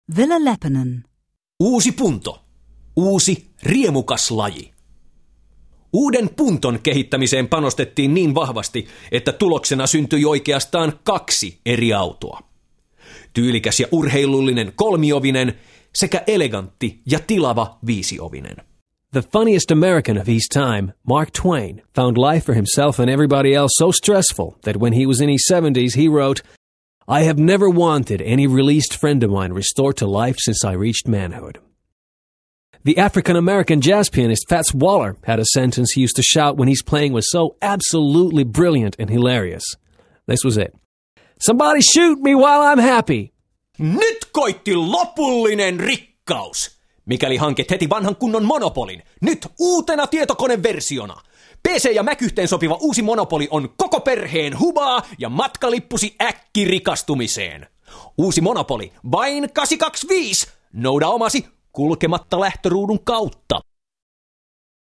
Finnish voiceover artist: contact his agent direct for male Finnish voice overs